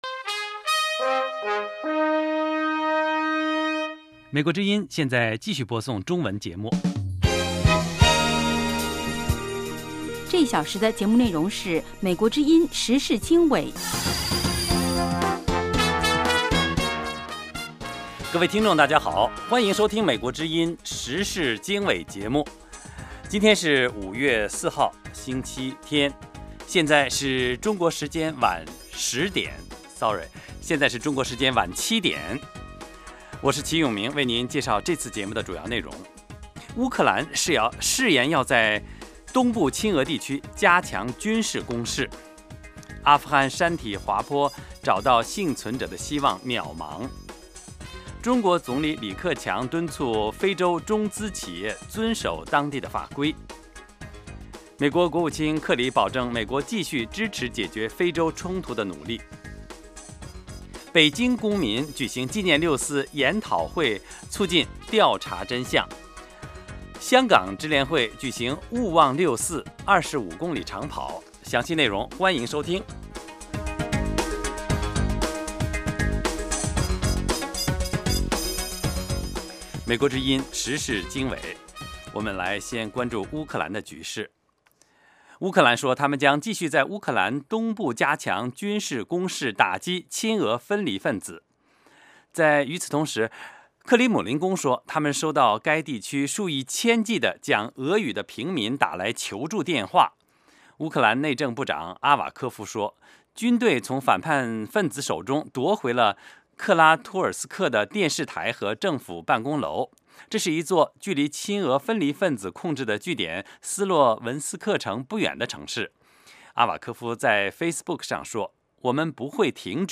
晚7-8点广播节目